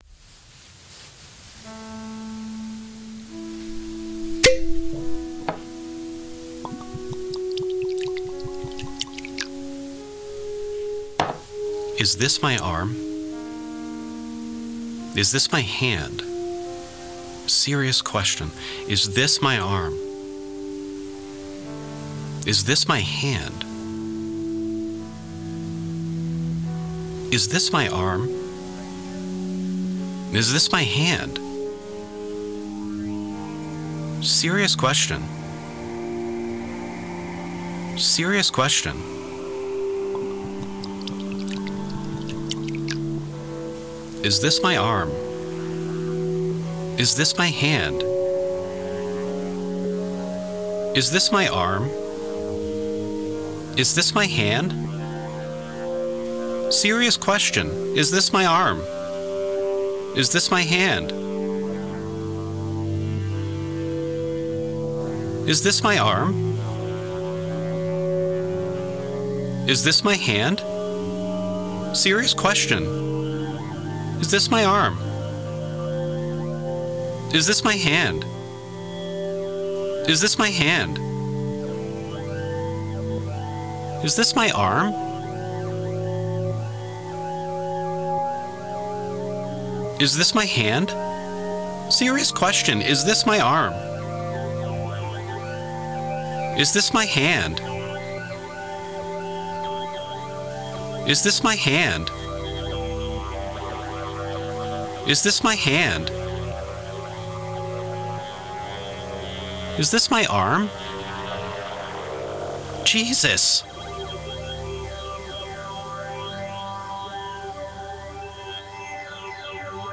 a spoken word poem